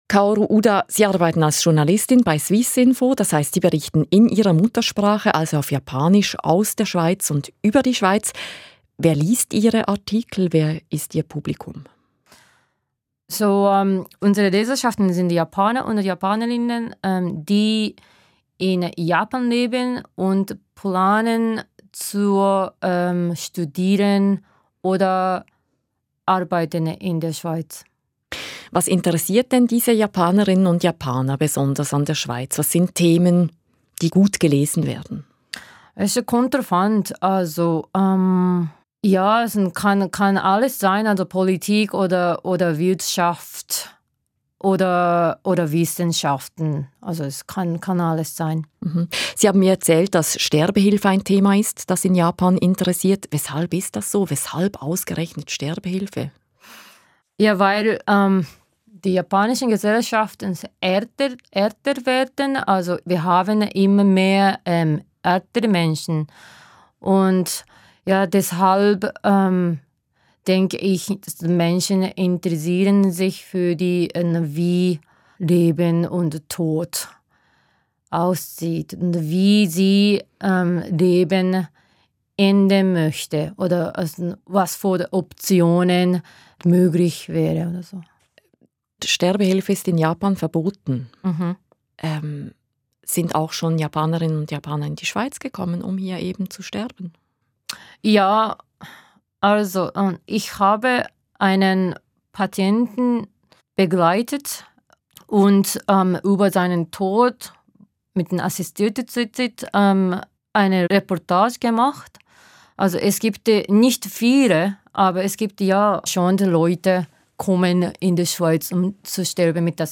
Vierter Teil des Rendez-vous Tagesgesprächs über Japan